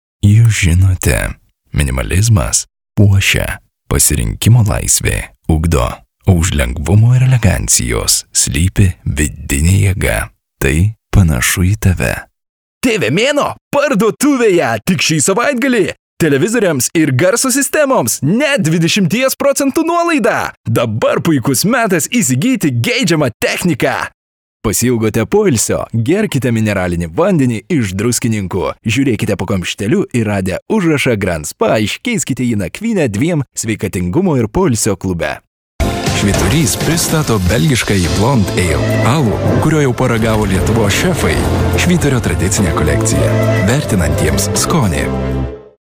Lithuanian voice over artist native
Sprechprobe: Werbung (Muttersprache):